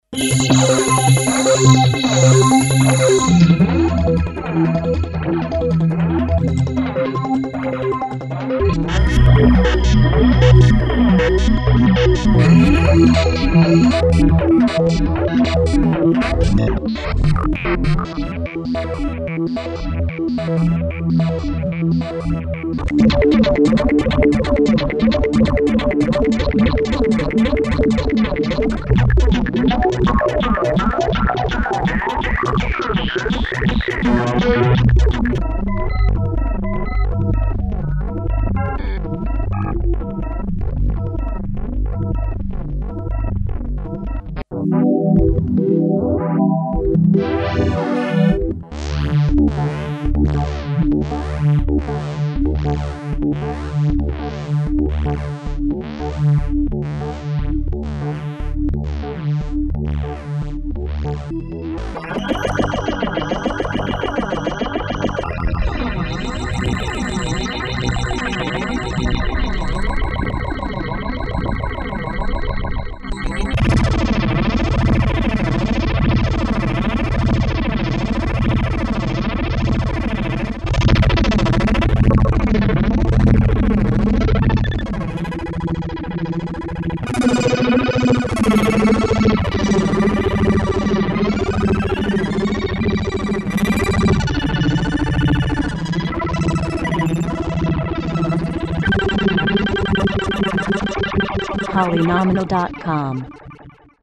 wavesequence tests